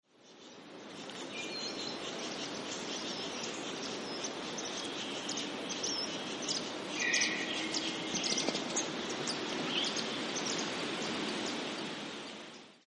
Varied Sittella - Daphoenositta chrysoptera
Voice: high-pitched 'seewit-seewee'; chip-chip contact calls.
Call 2: contact calls as a group flies overhead; a White-browed Scrubwren chatters to begin with.
Varied_Sittella_fly.mp3